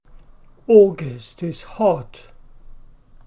/ˈɔːgəst/ /hɒt/